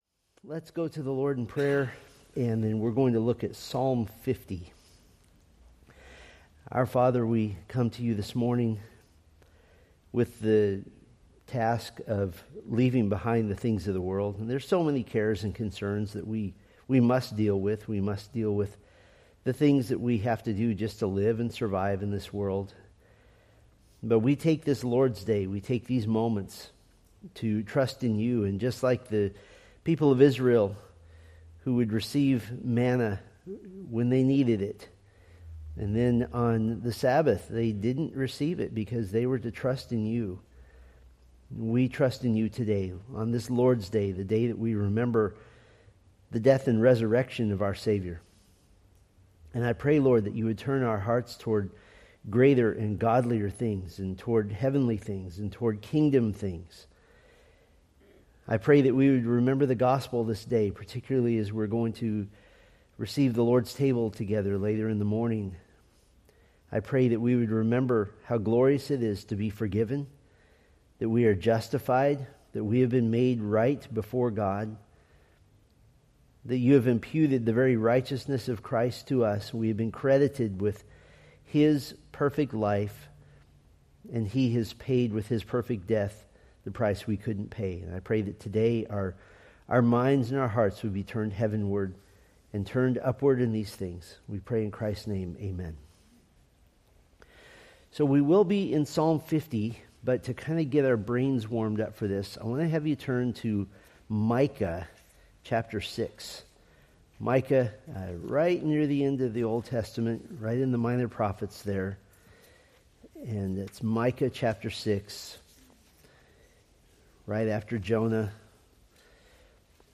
Date: Feb 8, 2026 Series: Psalms Grouping: Sunday School (Adult) More: Download MP3 | YouTube